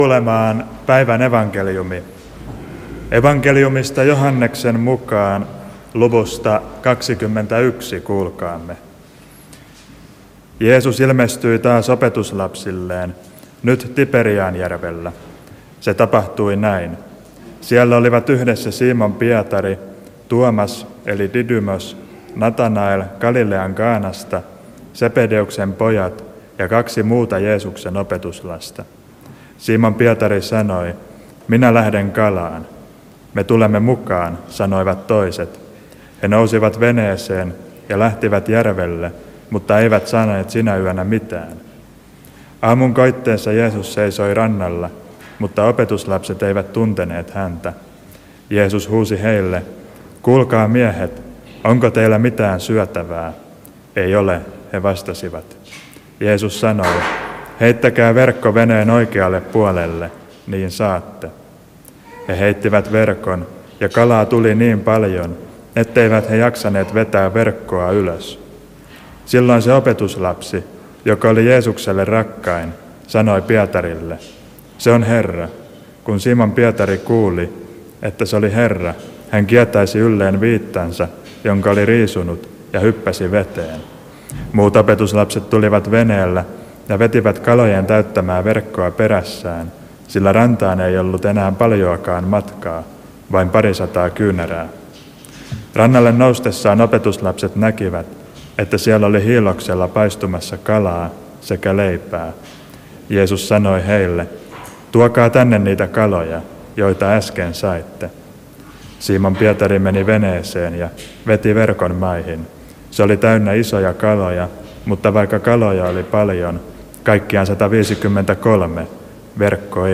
Lahdessa 1. sunnuntaina pääsiäisestä